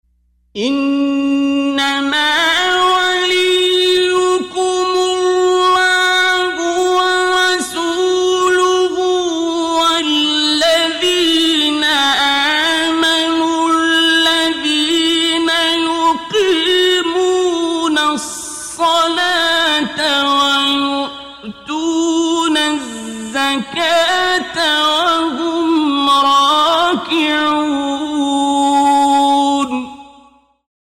تلاوت آیه ولایت با صوت عبدالباسط محمد عبدالصمد
برچسب ها: مقاطع صوتی از تلاوت ، آیه ولایت ، فرازی از تلاوت آیه ولایت ، تلاوت آیه ولایت از قاری مصری ، تلاوت آیه ولایت از قاری ایرانی